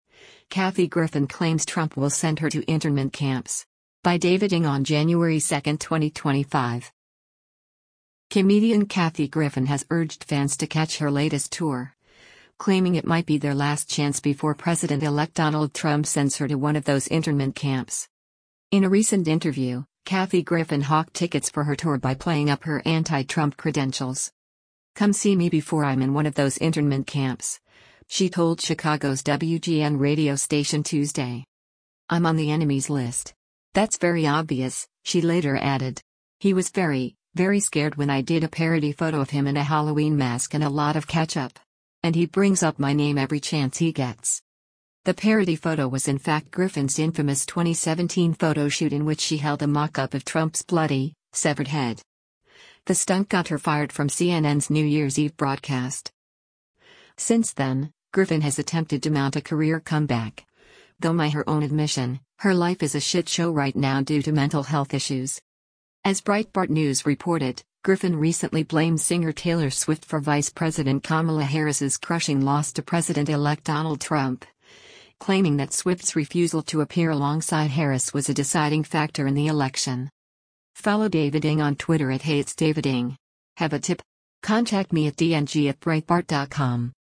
In a recent interview, Kathy Griffin hawked tickets for her tour by playing up her anti-Trump credentials.
“Come see me before I’m in one of those internment camps,” she told Chicago’s WGN radio station Tuesday.